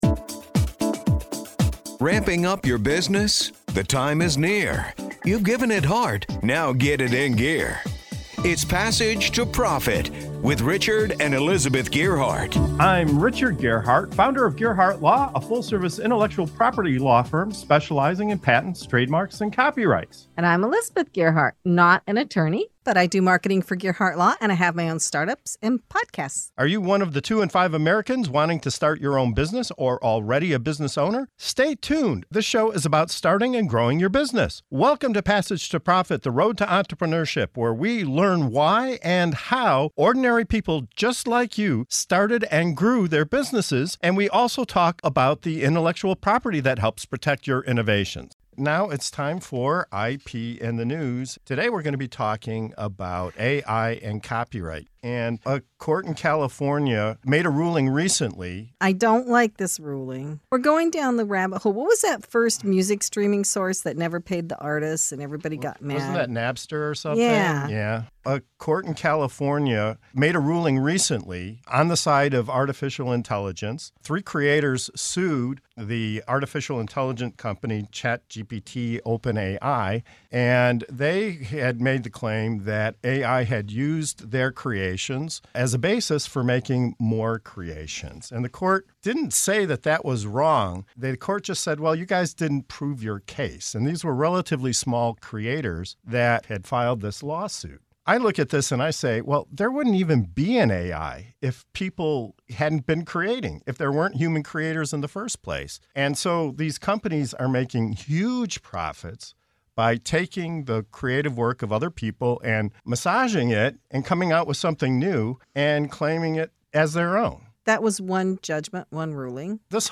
From Napster's echoes to the New York Times' legal battle with OpenAI, we dissect the challenges facing creators in the digital age and ponder the future of copyrights and intellectual property in an increasingly automated world. Tune in for a thought-provoking discussion on the blurred lines between inspiration and infringement.